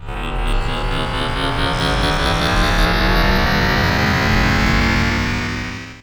SCIFI_Up_03_mono.wav